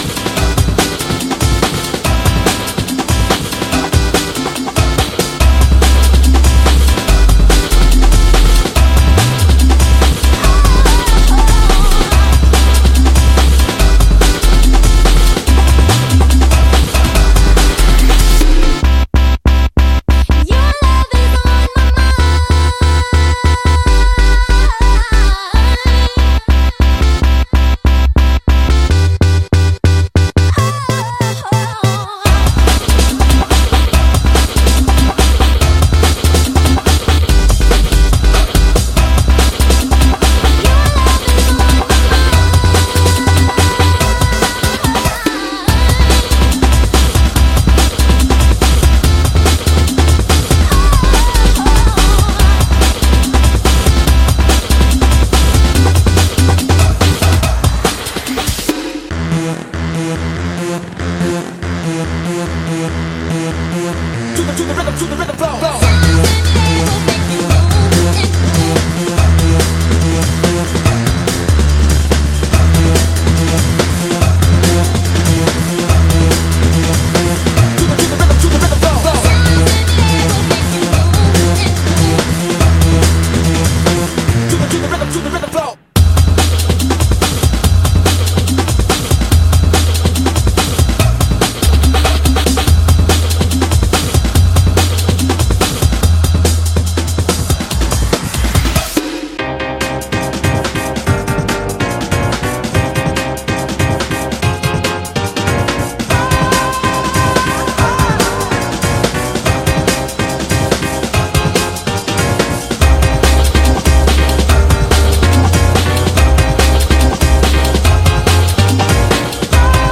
Breakbeat Hardcore / Jungle 12 Inch Single